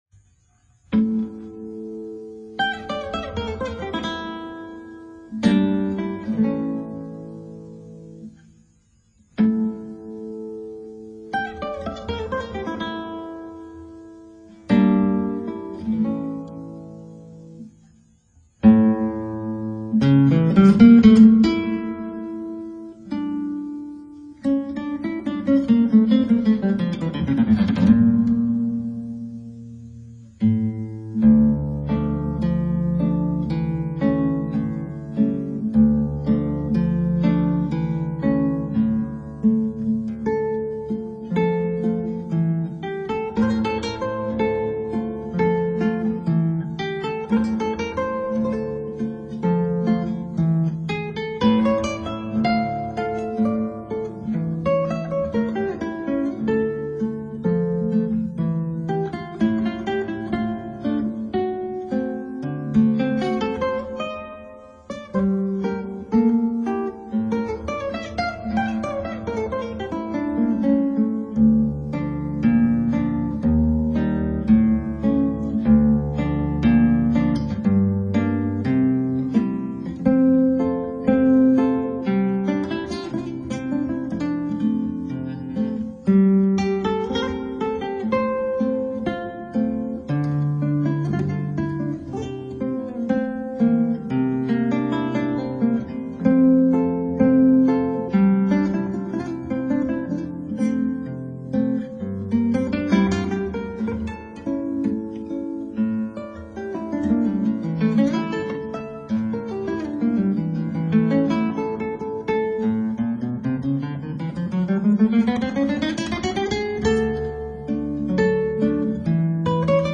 クラシックギター　ストリーミング　コンサートサイト
少し音のグレードを上げました。